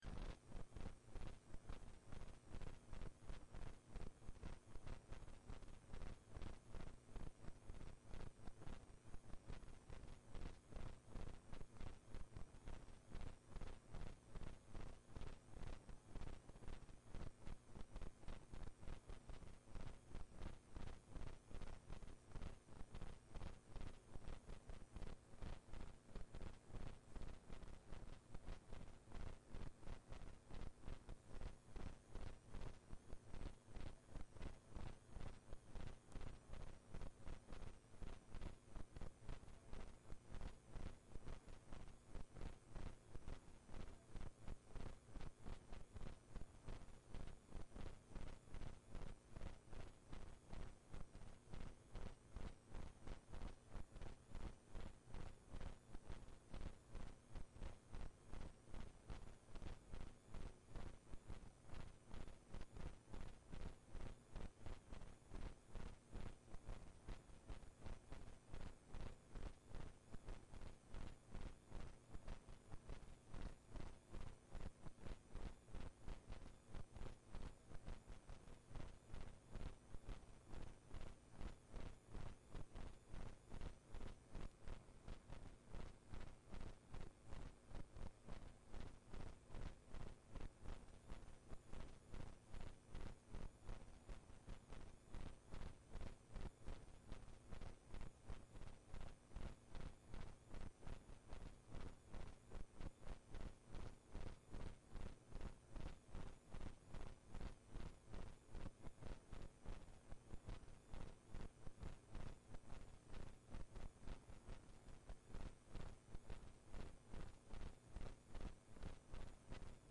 Kjemiforelesning 3
Rom: Smaragd 1 (S206)